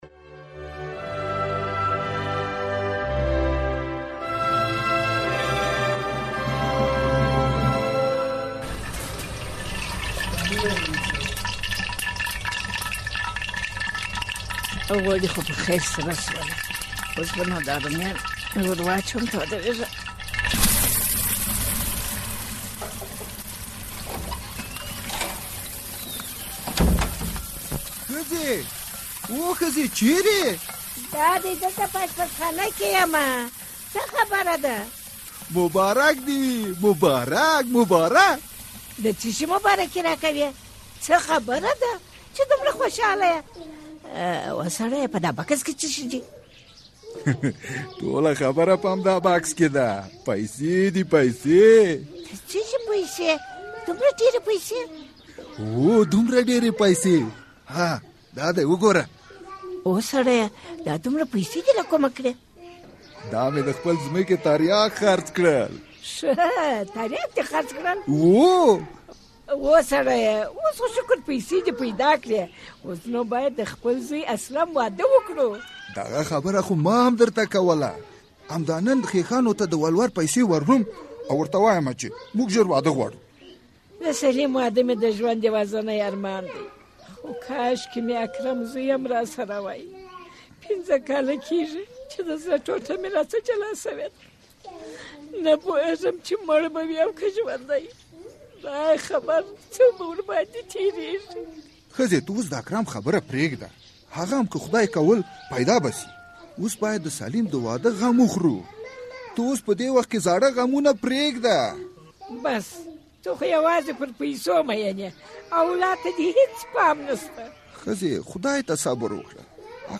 ډرامه